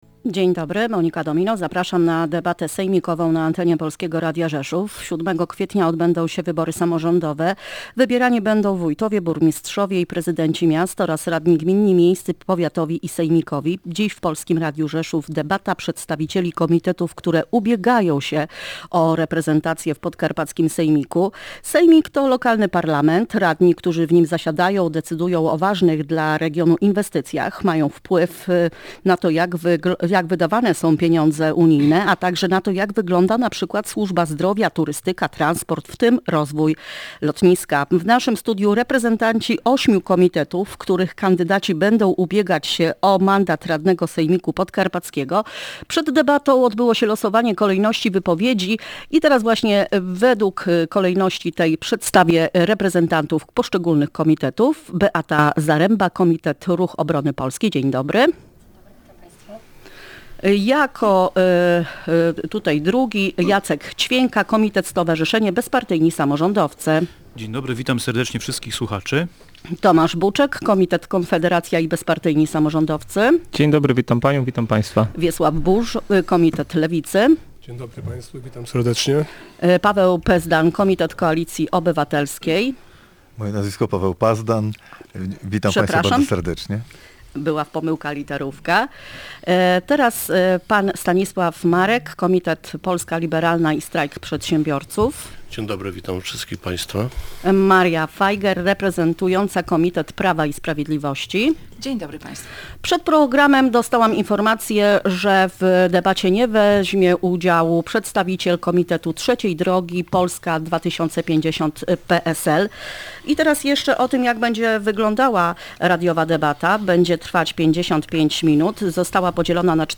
Debata przedstawicieli komitetów wyborczych
Na naszej antenie w czwartek gościliśmy siedmioro reprezentantów komitetów, których kandydaci będą walczyć o mandaty w podkarpackim sejmiku samorządowym.